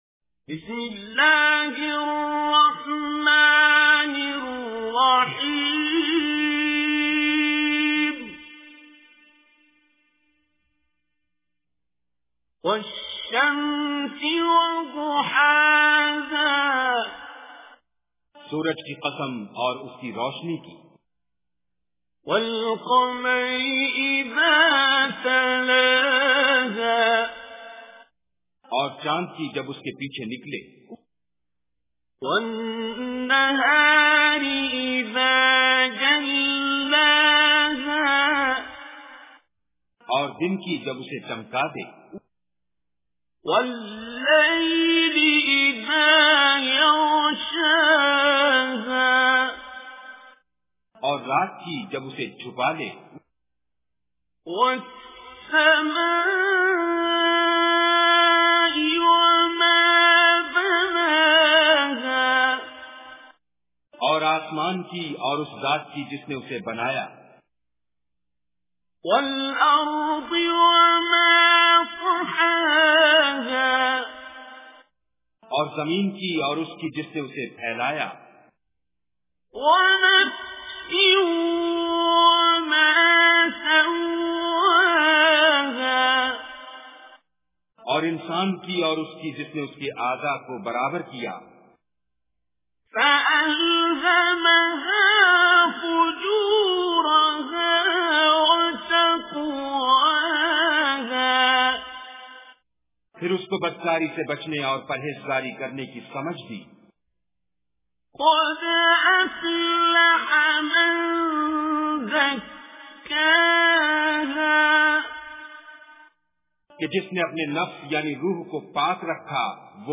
Surah Ash Shams Recitation with Urdu Translation
Listen online and download beautiful Quran tilawat / recitation of Surah Ash-Shams in the beautiful voice of Qari Abdul Basit As Samad.